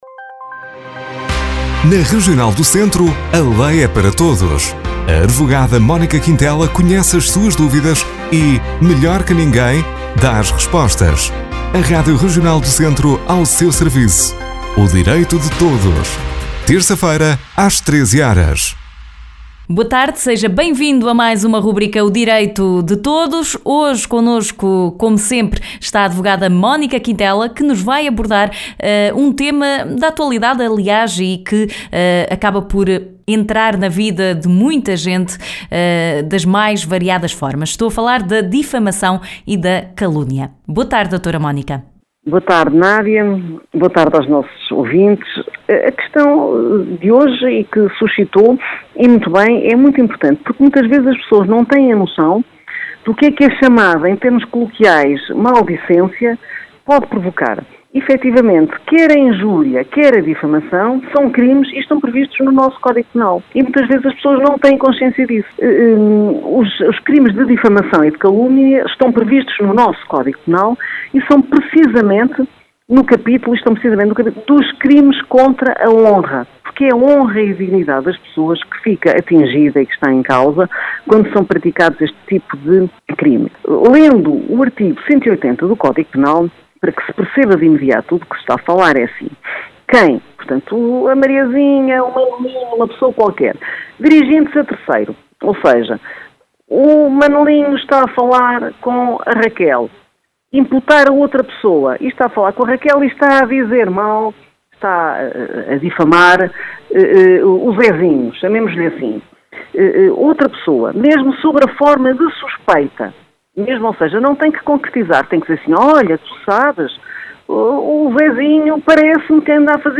Na rubrica de hoje a advogada Mónica Quintela esclarece o que são e quais as sanções legais da difamação e a calúnia.